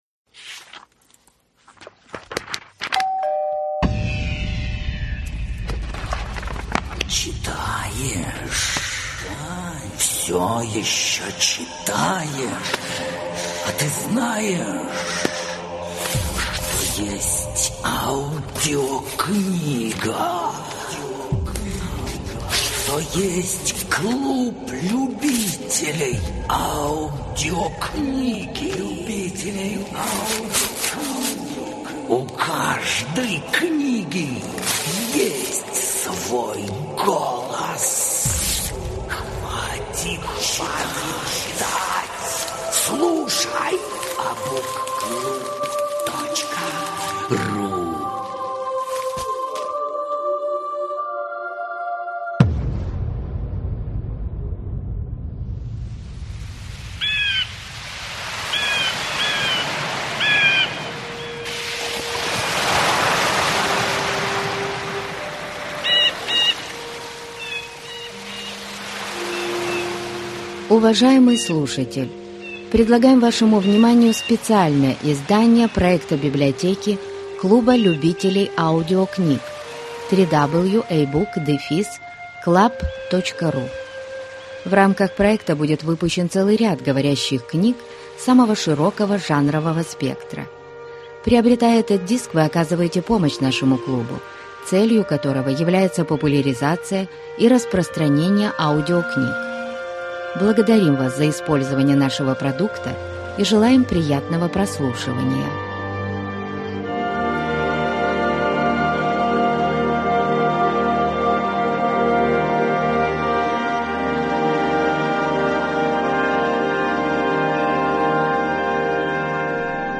Аудиокнига Плавающий город | Библиотека аудиокниг
Aудиокнига Плавающий город Автор Жюль Верн